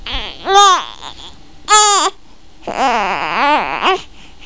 1 channel
babywhi.mp3